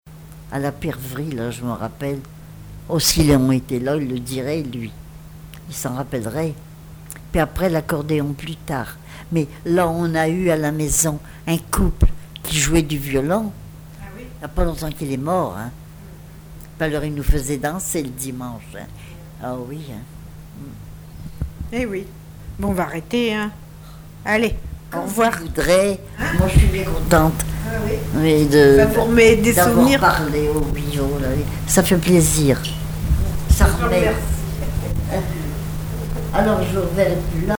témoignages sur le vécu de l'interviewée
Catégorie Témoignage